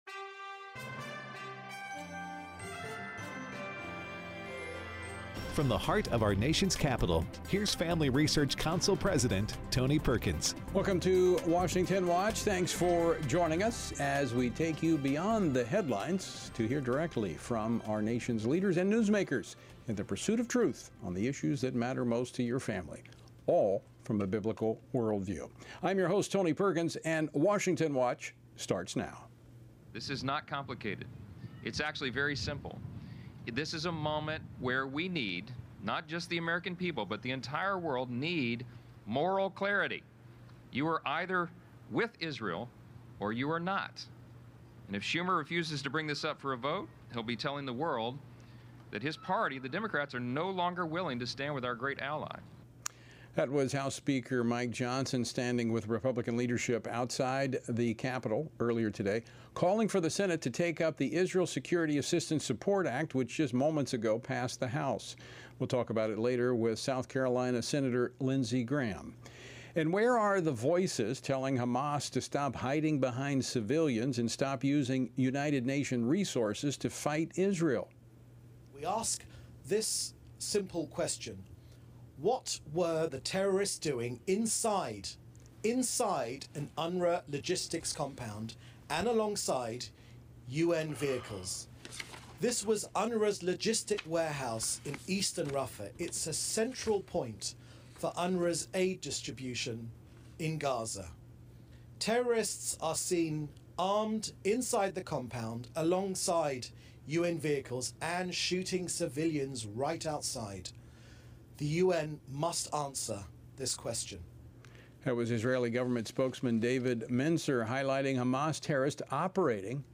Ted Cruz, U.S. Senator from Texas, reacts to the sentencing of a pro-life advocate following the Biden administration’s weaponization of the Freedom of Access to Clinic Entrances (FACE) Act and shares how the Biden administration’s war on energy hurts working families. James Comer, U.S. Representative for the 1st District of Kentucky, discusses the Chinese Communist Party’s effort to infiltrate U.S. institutions.
Lindsey Graham , U.S. Senator from South Carolina, provides analysis of the Biden administration’s decision to withhold munitions from Israel for its war against Hamas and responds to President Biden using executive privilege to block the release of recordings from the investigation into his mishandling of classified documents.